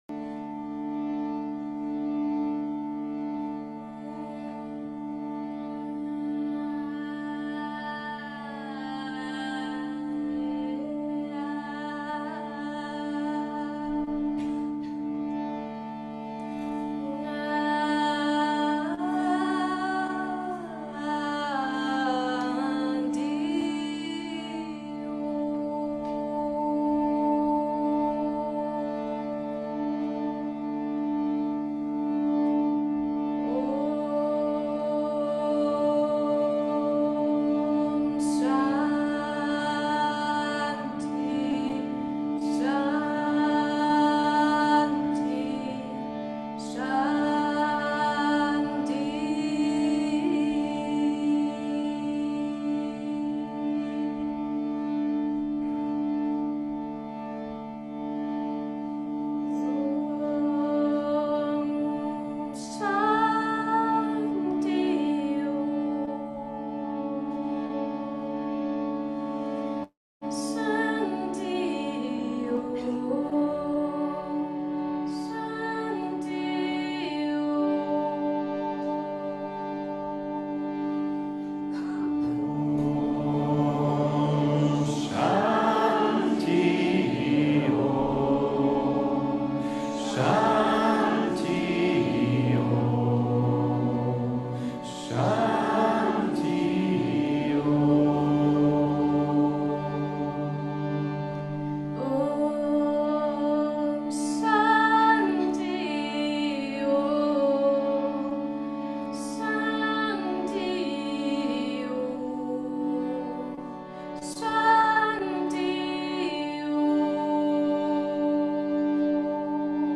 Shanti Om, Yoga Vidya Satsang Januar 2015